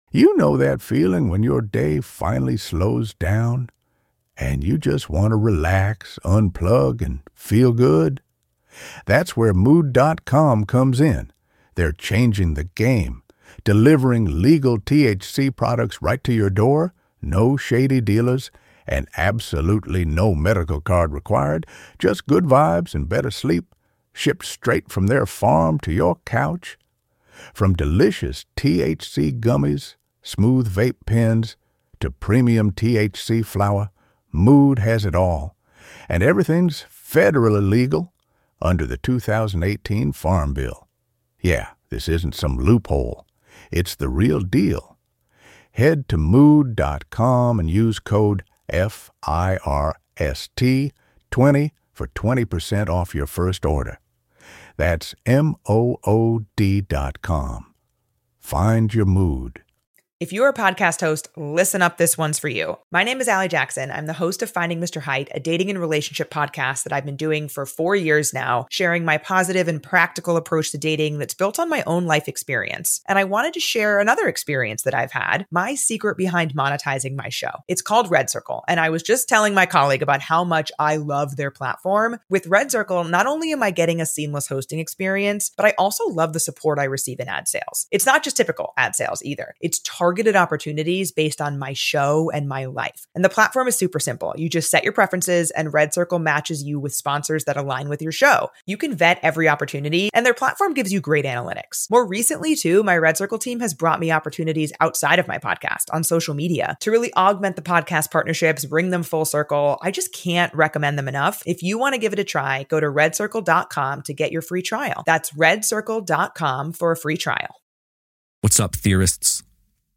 Here on Theorists Theorizing, we don’t just tell stories—we dive headfirst into the shadows of mystery, battling it out in spirited debate. We thrive on the clash of perspectives, where skeptics lock horns with believers, and every mystery is put on trial in the court of conversation.